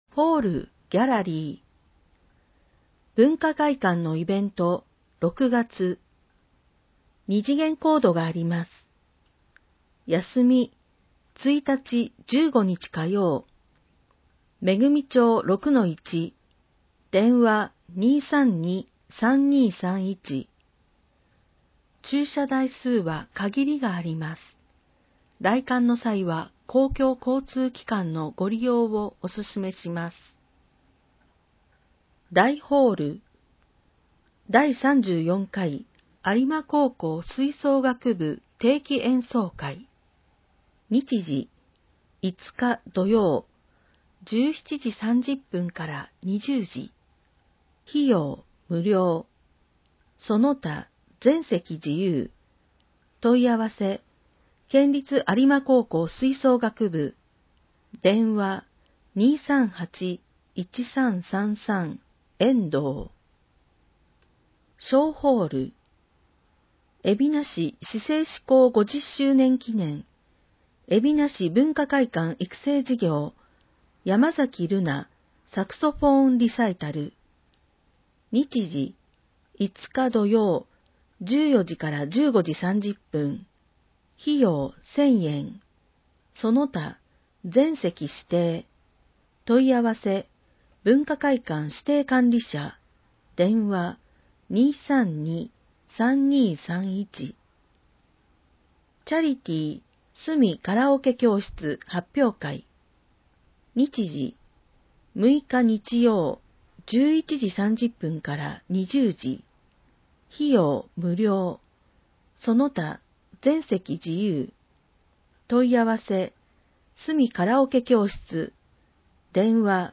広報えびな 令和3年5月15日号（電子ブック） （外部リンク） PDF・音声版 ※音声版は、音声訳ボランティア「矢ぐるまの会」の協力により、同会が視覚障がい者の方のために作成したものを登載しています。